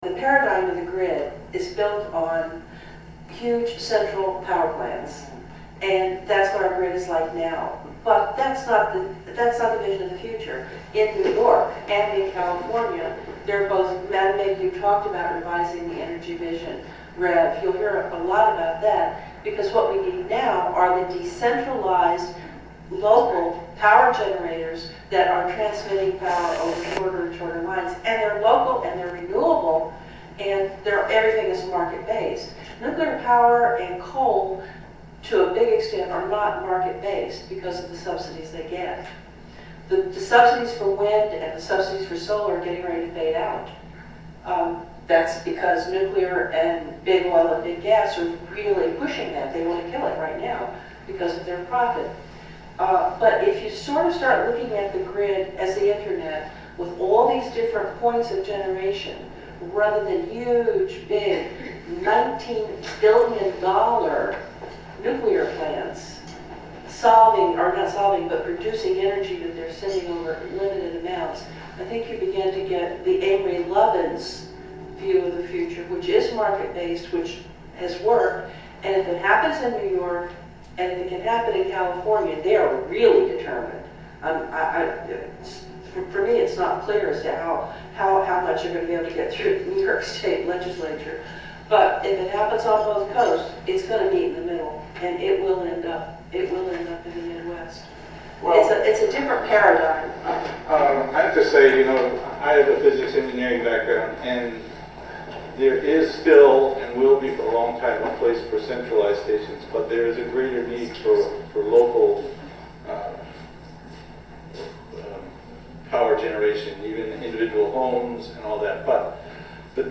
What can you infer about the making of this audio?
Hudson Hall at the Historic Hudson Opera House (10:48) An excerpt from a panel discussion following the screening of "Uranium Drive-In" at the Hudson Opera House, Sun., Mar. 22.